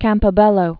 (kămpə-bĕlō)